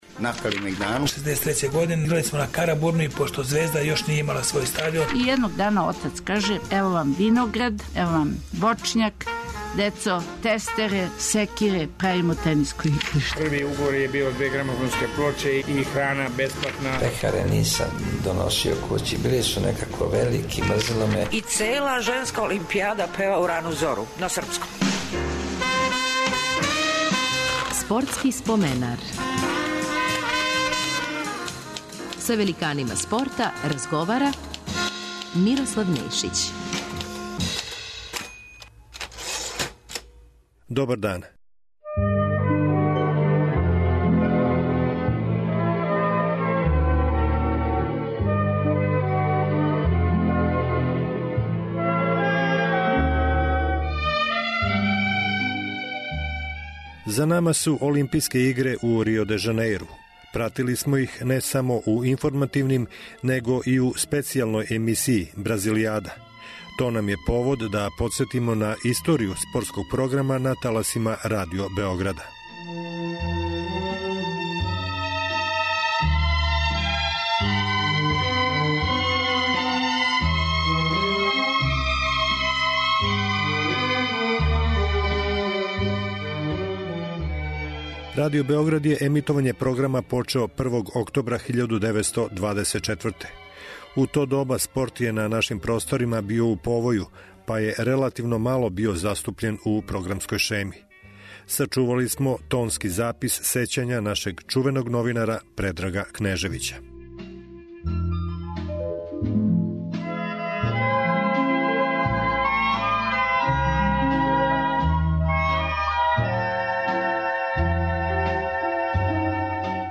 Бићете у прилици да поново чујете инсерте из комбинованих преноса фудбалских утакмица